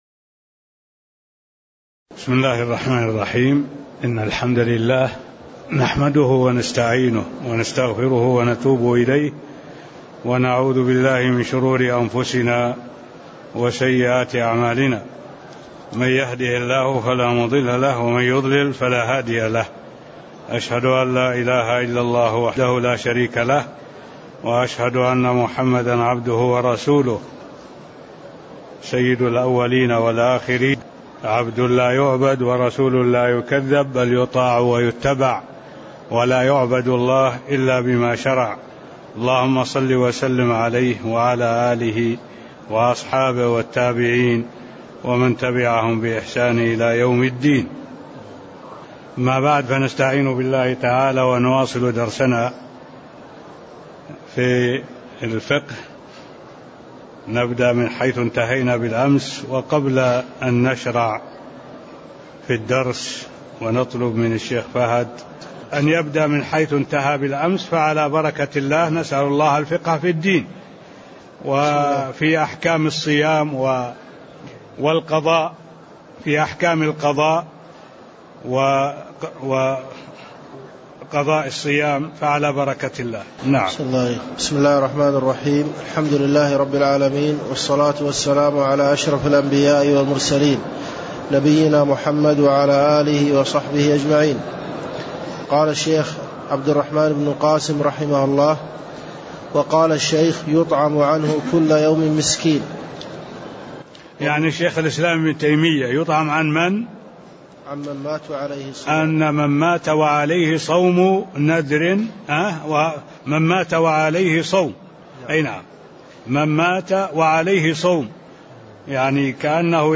المكان: المسجد النبوي الشيخ: معالي الشيخ الدكتور صالح بن عبد الله العبود معالي الشيخ الدكتور صالح بن عبد الله العبود أحكام الصيام و القضاء(قول المصنف ويطعم عنه كل يوم مسكين) (11)